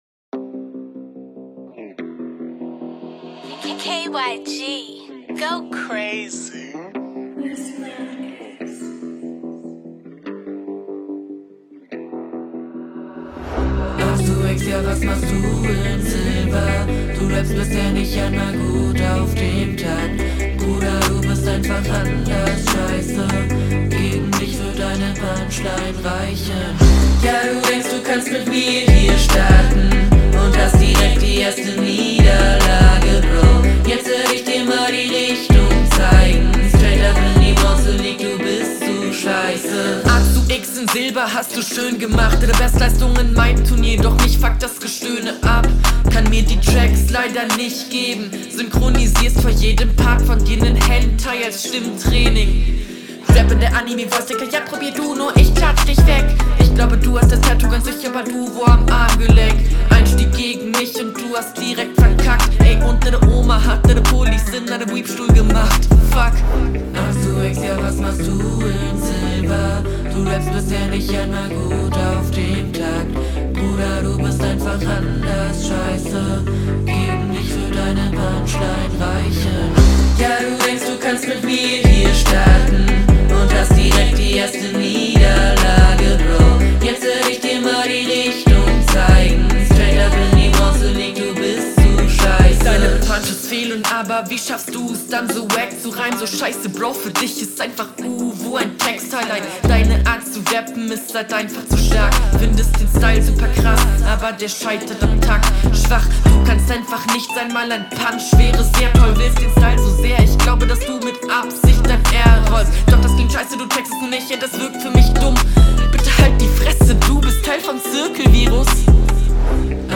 Ich mag den Gesang aber bei den normalen Passagen klingt die Stimme viel zu dünn,aber …